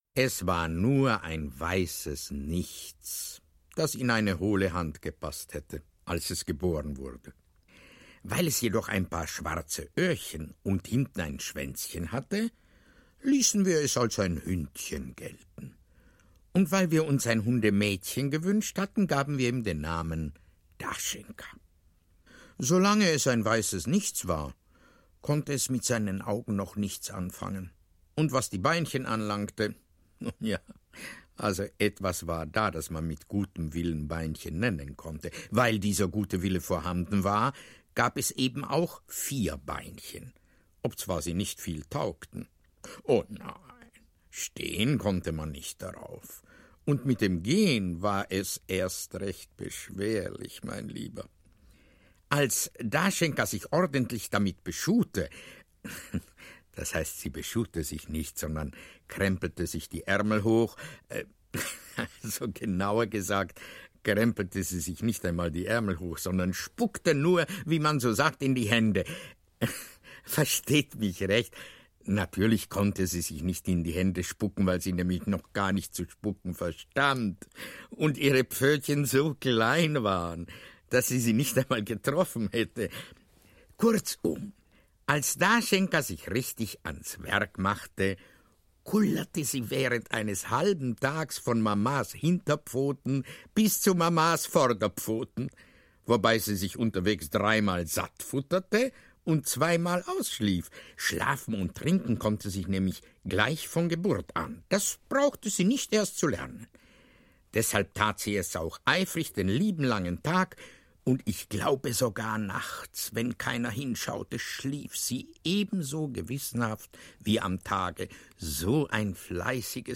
Audio knihaDaschenka oder das Leben eines jungen Hundes
Ukázka z knihy
• InterpretValtr Taub